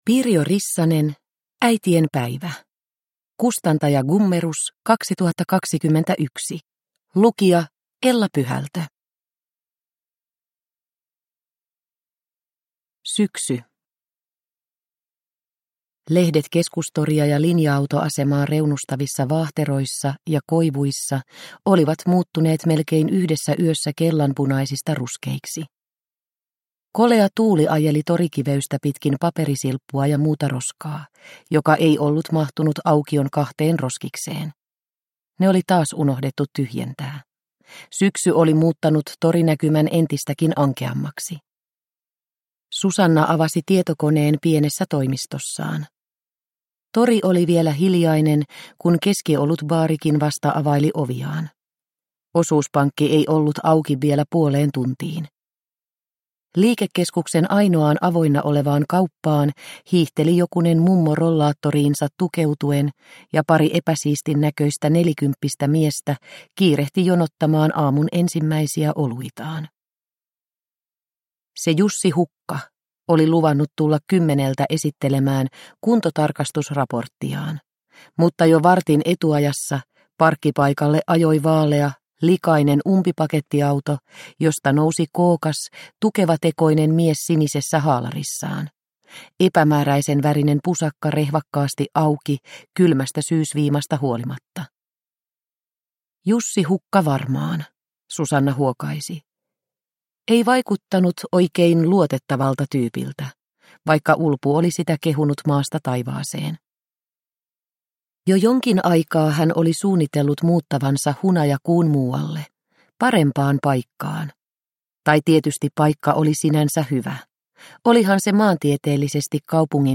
Äitienpäivä – Ljudbok – Laddas ner